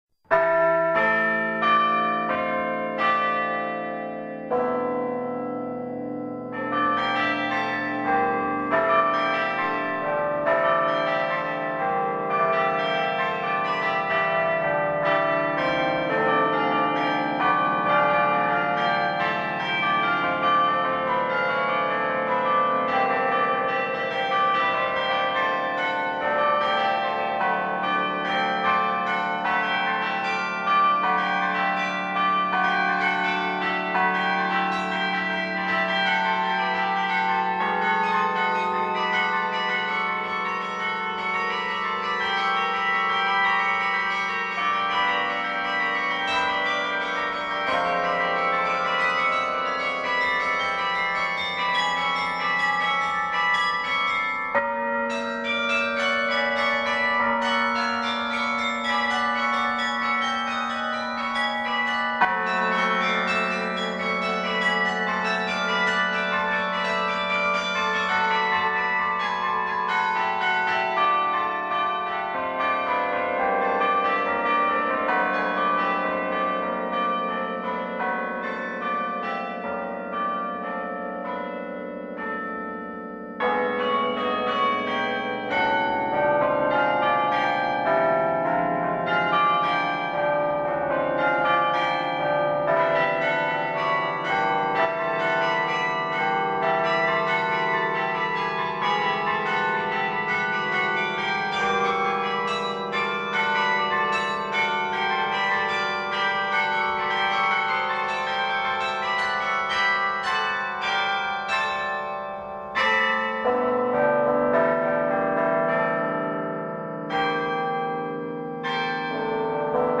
Fleischmann Carillon Recital 13 June 2010 in St Colman’s Cathedral, Cobh
Fleischmann: Toccata for Carillon